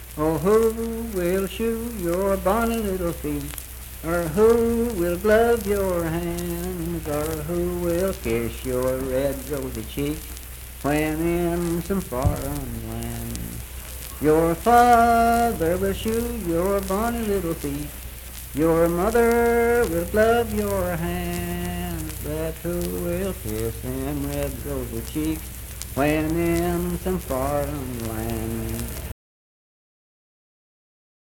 Unaccompanied vocal music performance
Verse-refrain 2(4).
Voice (sung)
Calhoun County (W. Va.)